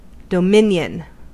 Ääntäminen
IPA : /dəˈmɪn.jən/